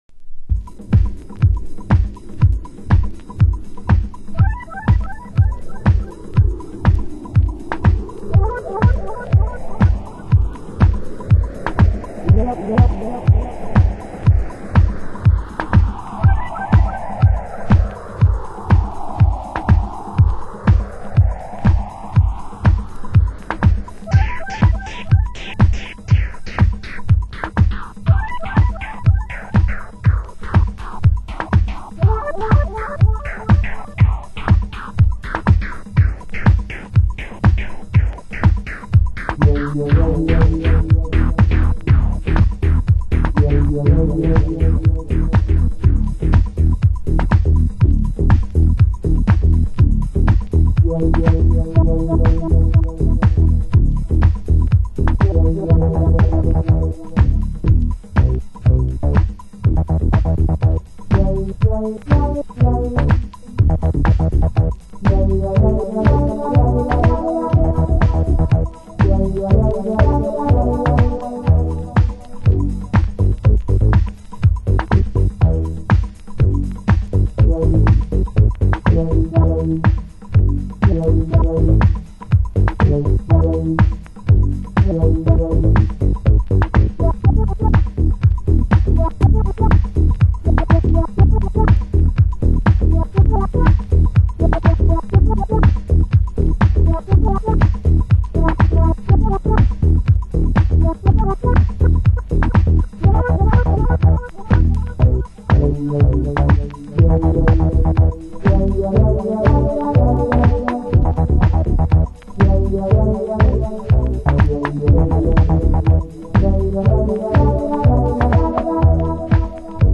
HOUSE MUSIC
盤質：少し歪み有/盤面良好ですが、少しチリパチノイズ有